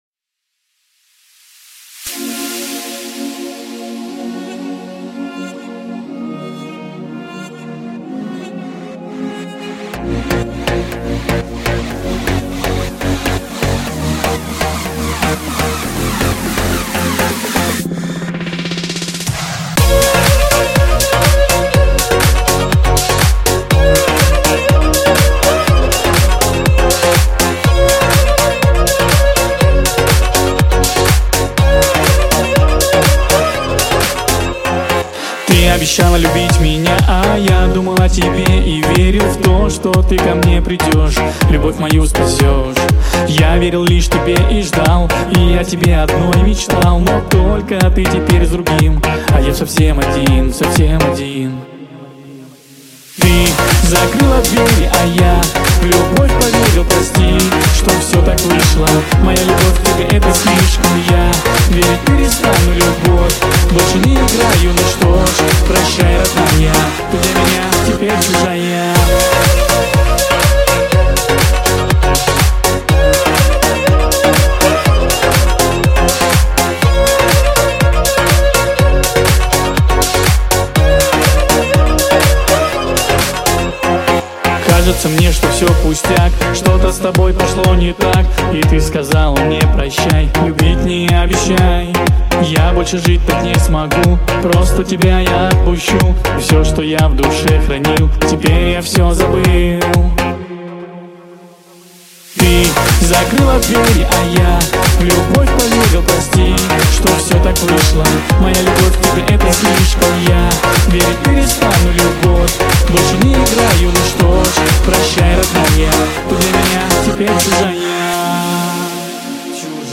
Erger 2017, Армянская музыка